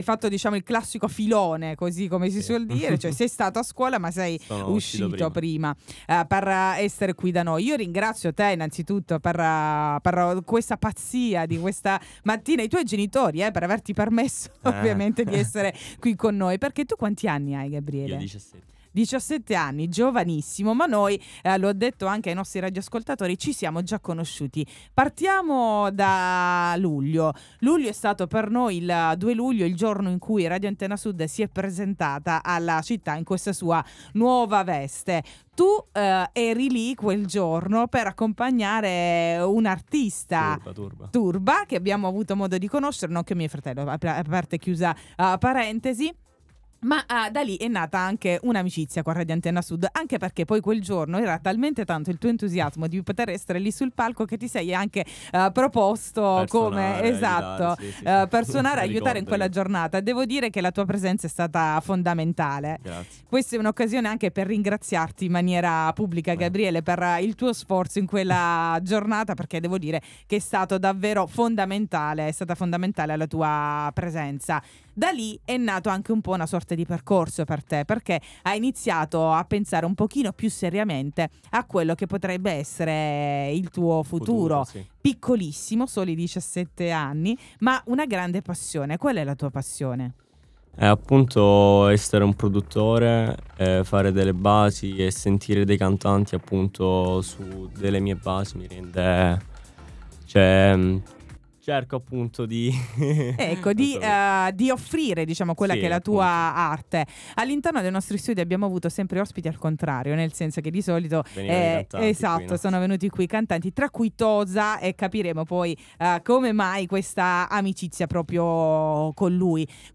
oggi nei nostri studi